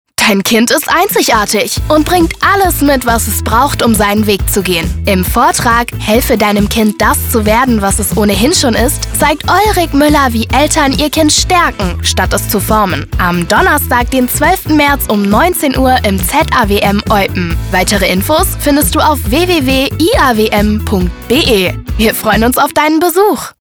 In die Arbeit vorm Mikrofon ist sie quasi hineingewachsen und wird auch heute noch mitunter als aufgeweckte junge "Kinderstimme" in der Werbung oder auch für Hörspiele oder Synchron gebucht.
Funk 2026